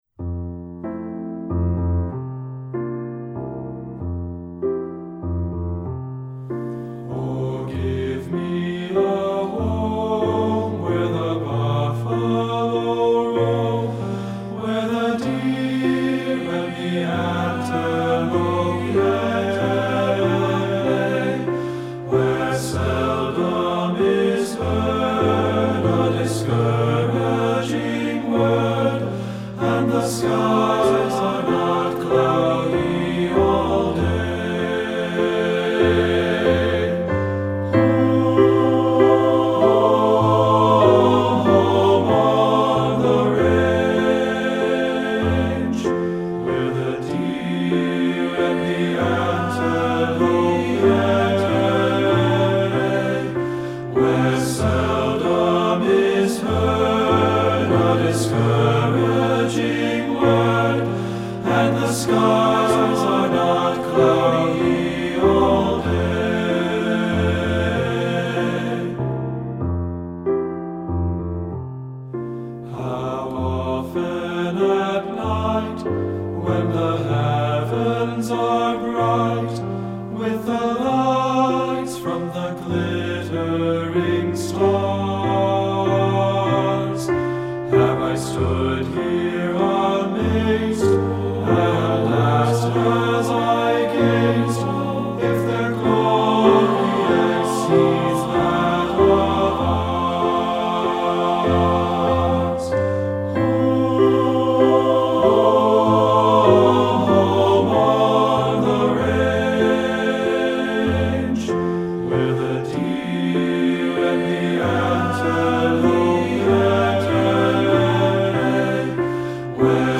Composer: American Folk Song
Voicing: TB